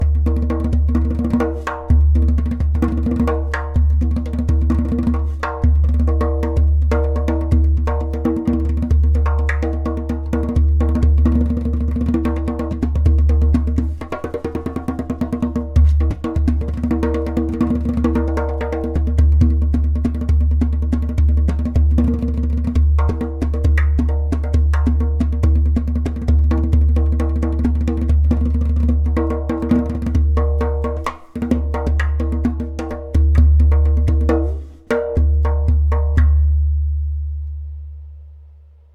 Premium True Bass Dohola
• Strong and super easy to produce clay kik (click) sound
• Very Deep bass
• Even tonality around edges.
• Beautiful harmonic overtones.
• Skin: Goat skin